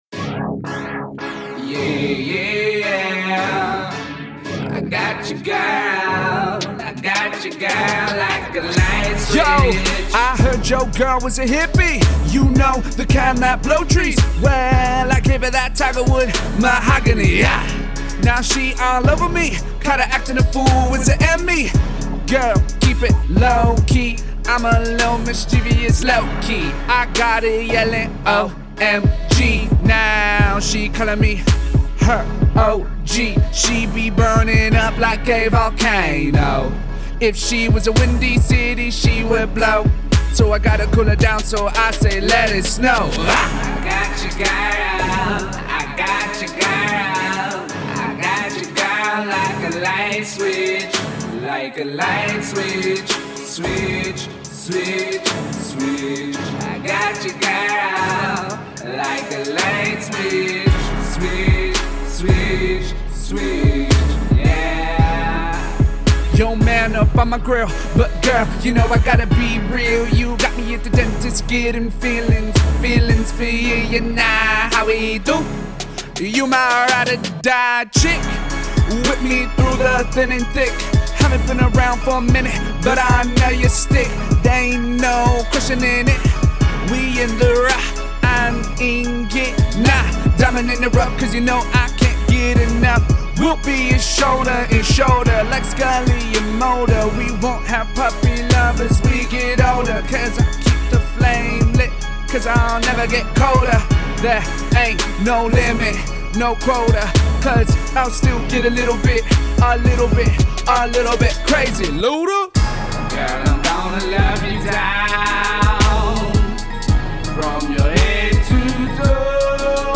Hiphop
Raps are infused with R & B, Rock and Jazz.